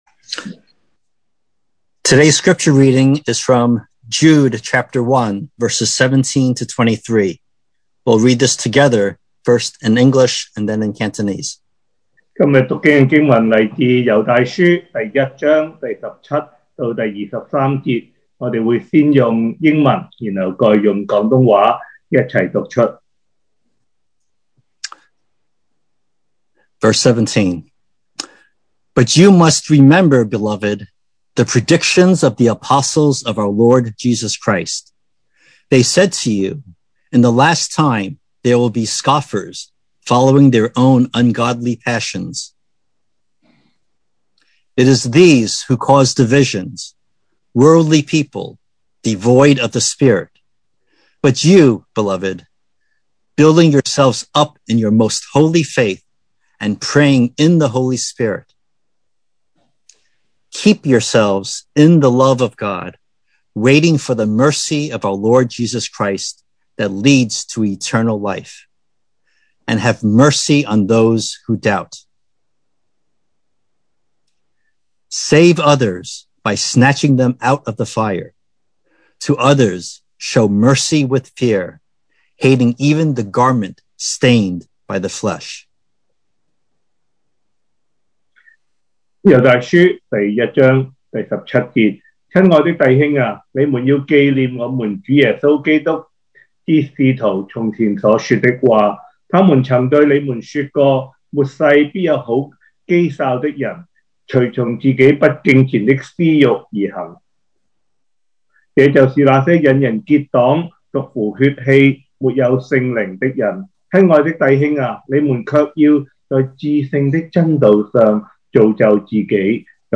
2021 sermon audios 2021年講道重溫 Passage: Jude 1:17-23 Service Type: Sunday Morning What is God doing in our life right now?